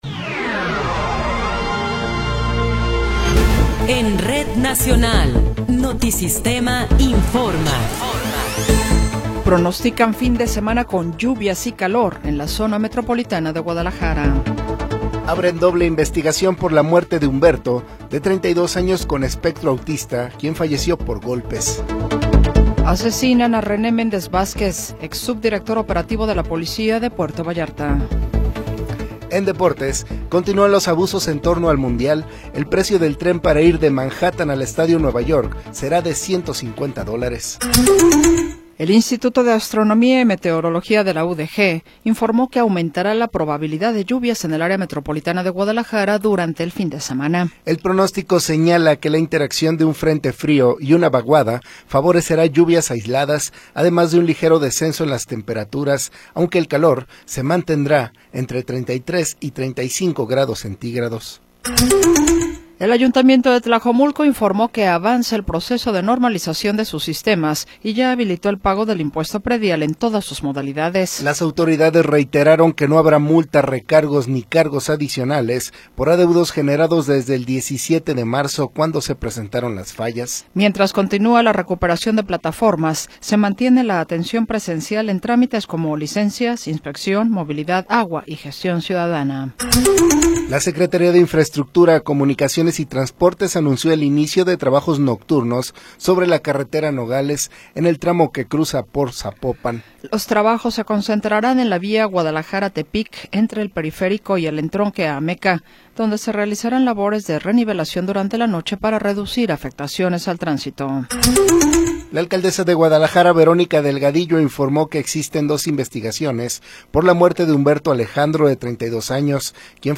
Noticiero 20 hrs. – 17 de Abril de 2026